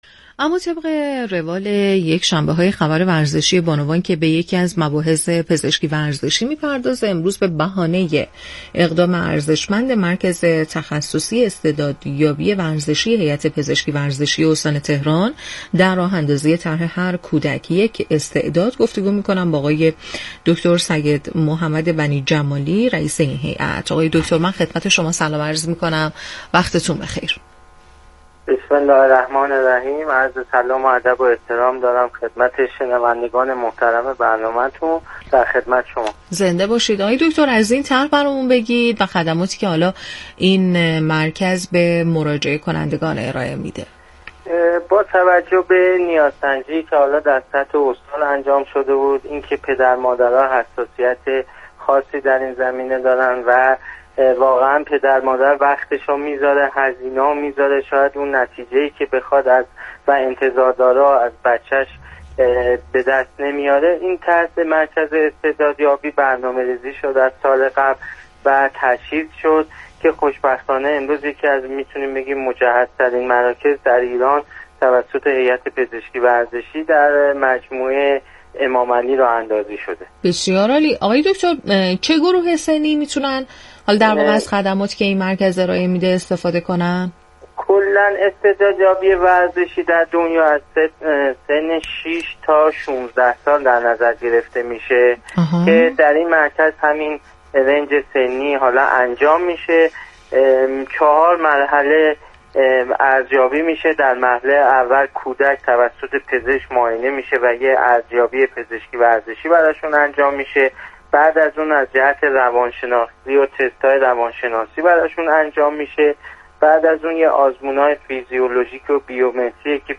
در گفت وگو با رادیو ورزش: